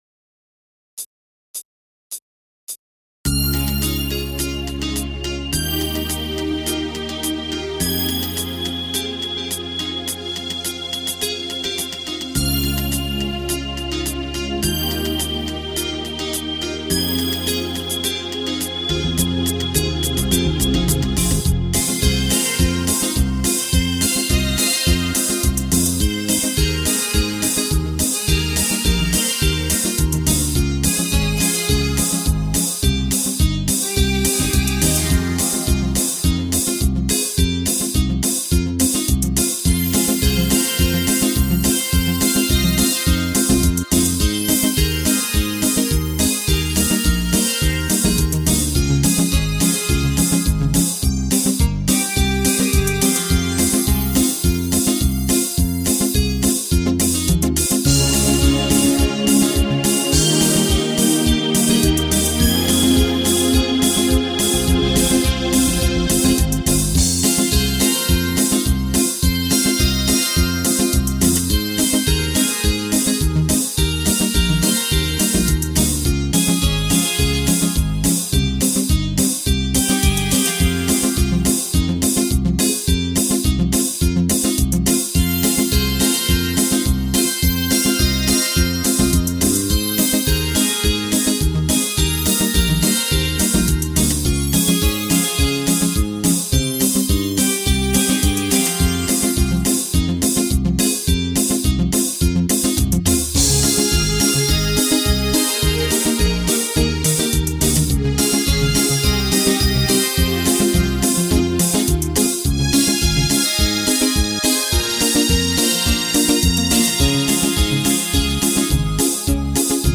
Минусовки Минус Фонограмма Караоке